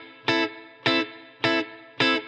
DD_TeleChop_105-Amin.wav